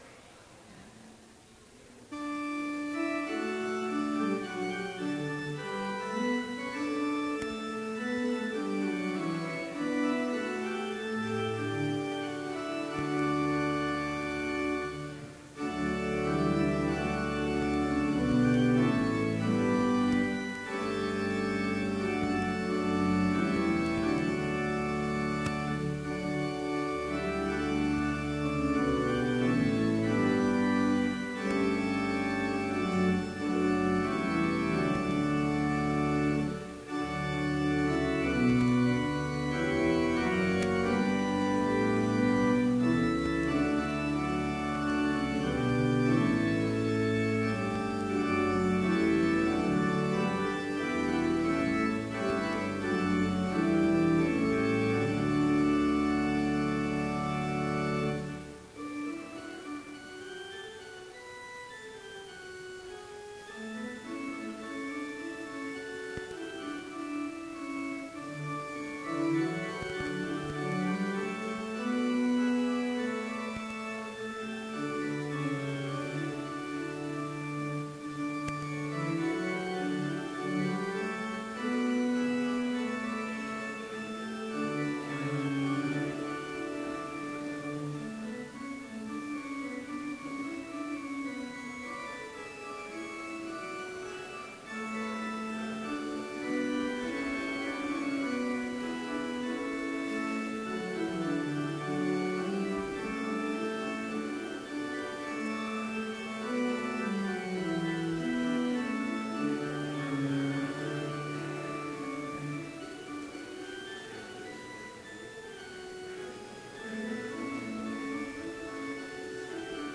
Complete service audio for Chapel - May 3, 2016